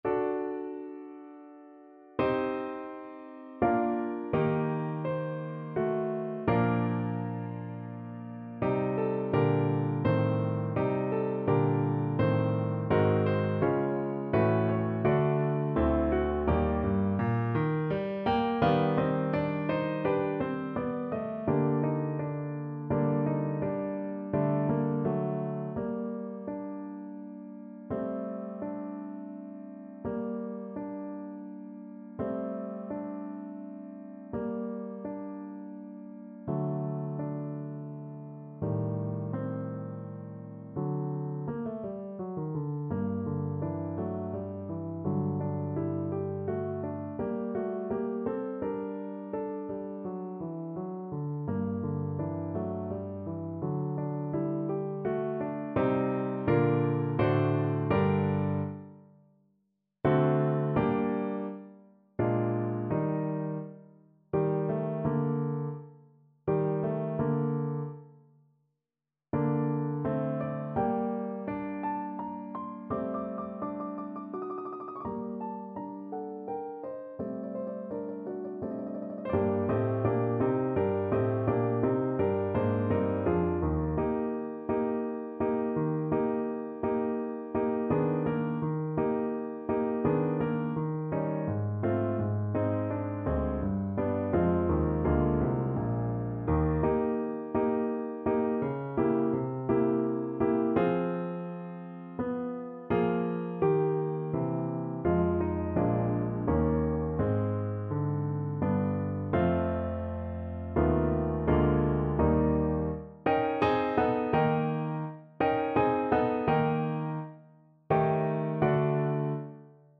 3/4 (View more 3/4 Music)
Andante =84
Classical (View more Classical French Horn Music)